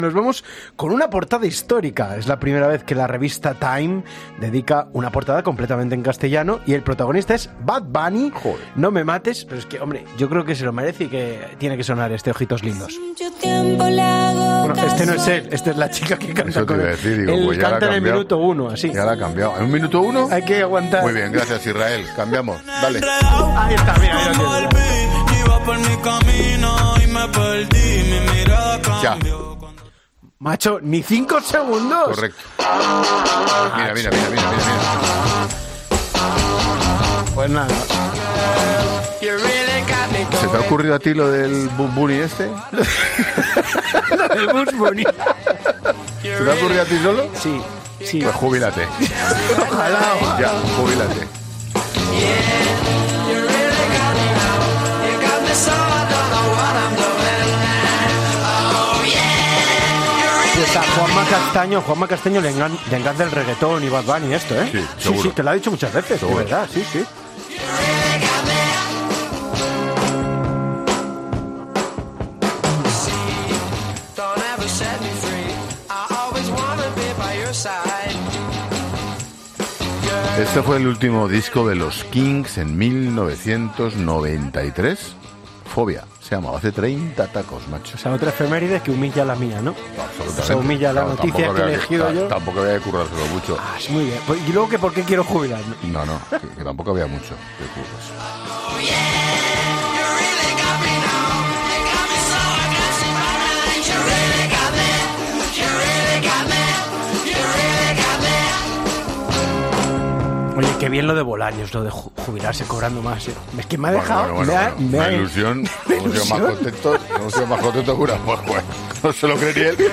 La reacción de Ángel Expósito cuando suena Bad Bunny en directo en La Linterna: "El Bugs Bunny este..."